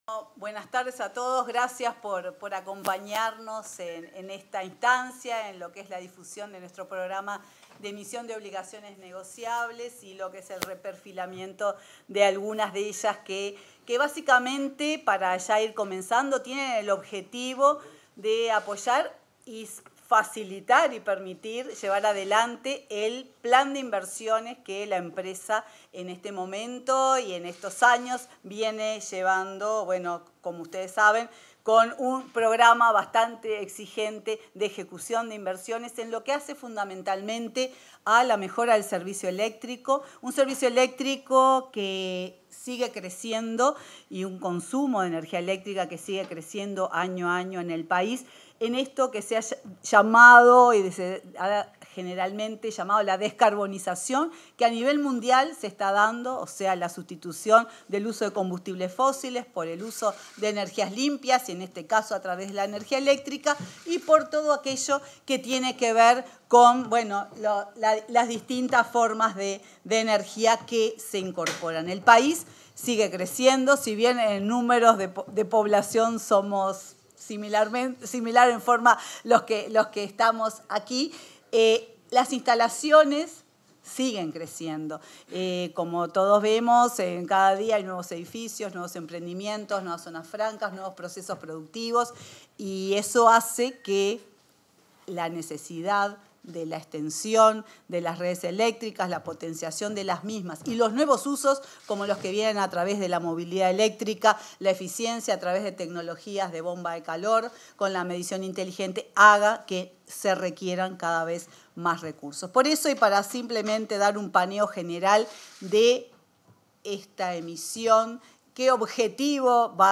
Palabras de la presidenta de UTE, Silvia Emaldi